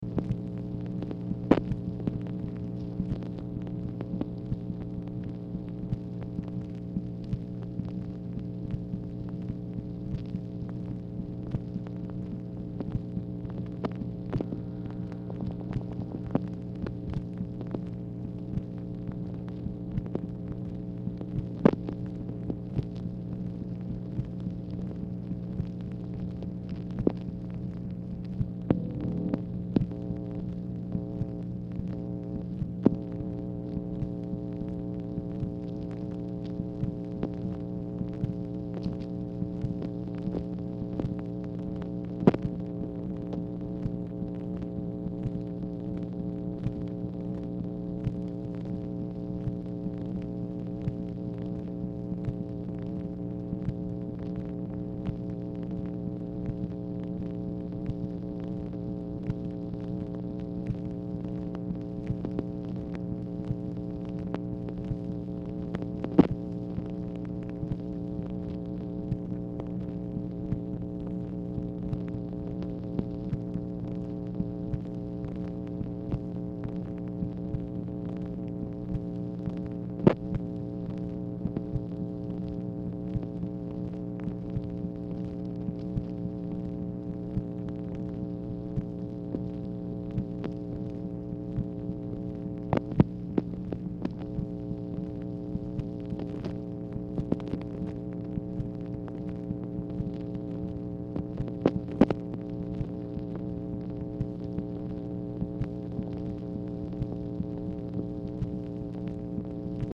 Telephone conversation # 8909, sound recording, MACHINE NOISE, 9/30/1965, time unknown | Discover LBJ
Format Dictation belt